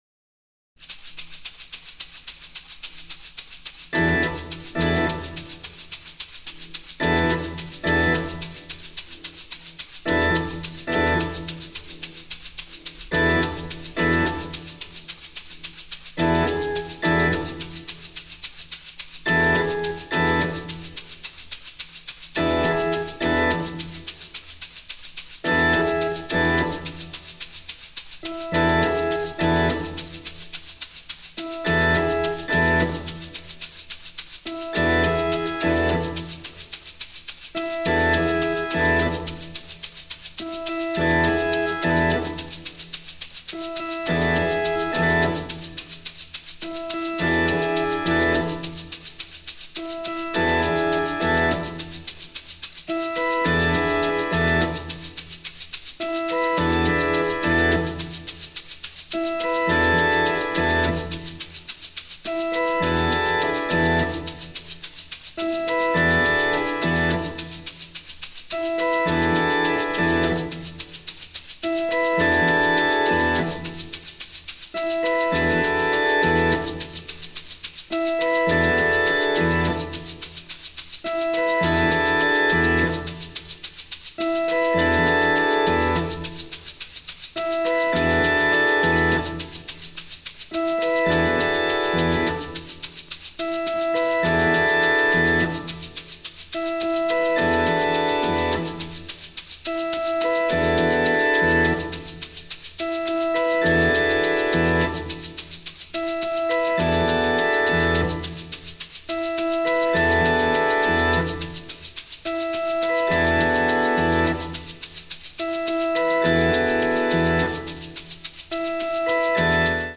オルガンのギラついた音色がサイケデリック感覚を発露させるミニマリズムの超人気作！
ドローンのような強烈な高揚感や酩酊感を醸し出していますね！一定のリズムを刻み続けるマラカスが非常に効いていますよ！